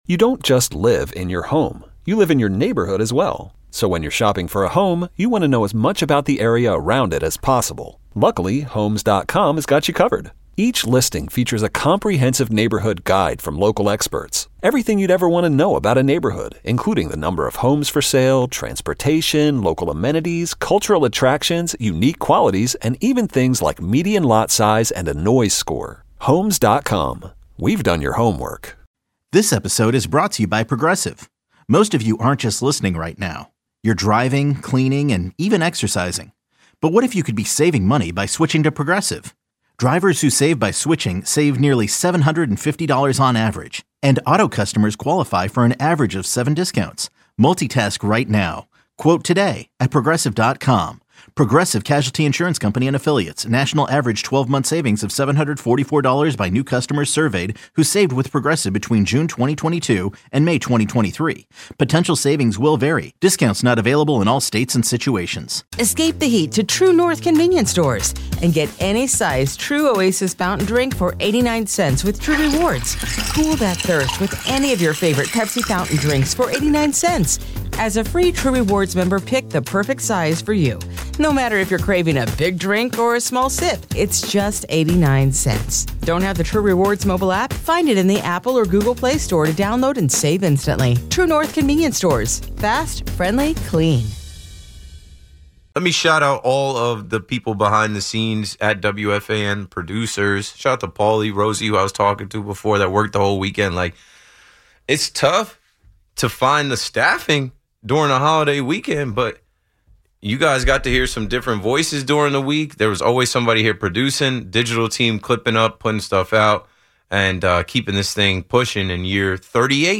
Latest was FULL SHOW: Ice Cube & Dwight Howard Interview, Nicki Minaj Says Roc Nation Is Trying To Sabotage Her, Jay-Z Pops Out At Beyoncé Show + More.